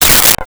Space Gun 03
Space Gun 03.wav